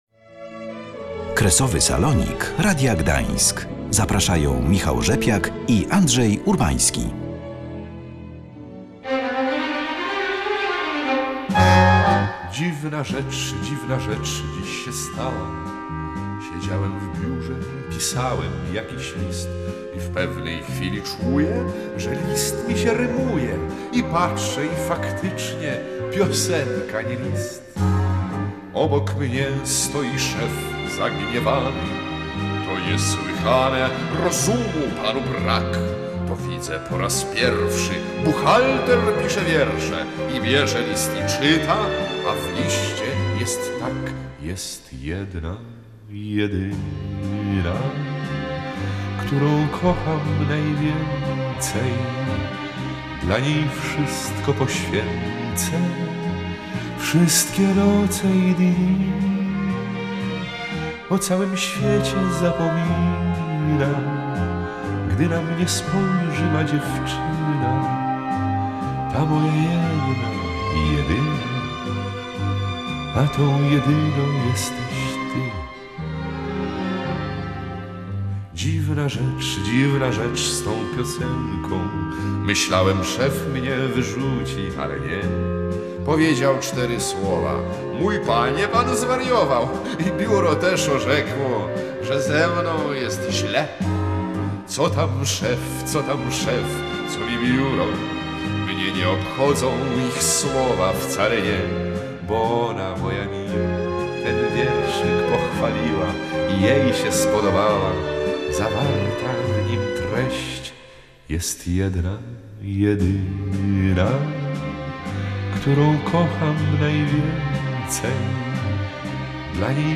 Jak przedstawia się sytuacja „od środka”? Tego postaraliśmy się dowiedzieć od naszych lwowskich korespondentów.